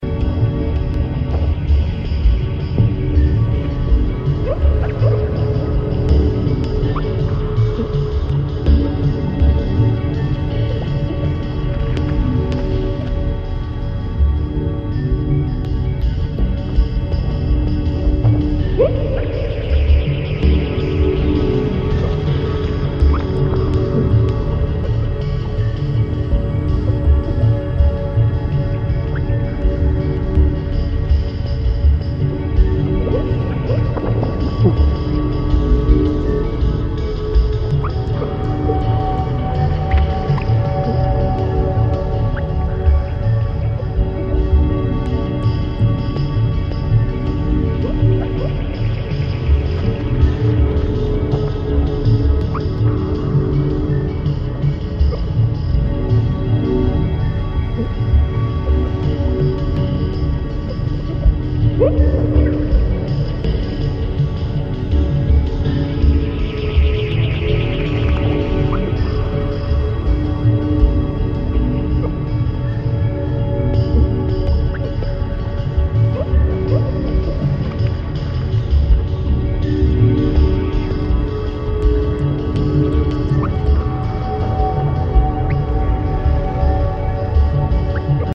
アクアティックなイメージの